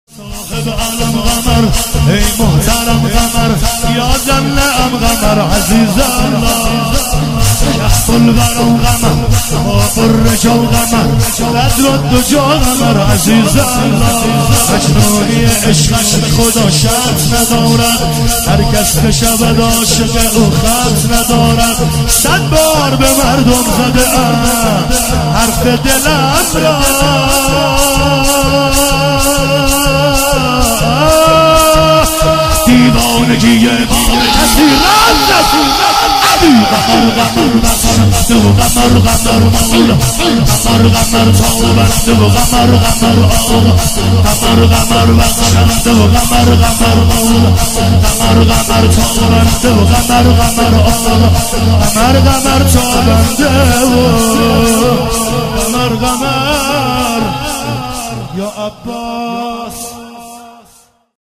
شهادت حضرت معصومه (س) | هیئت جانثاران امام زمان (عج)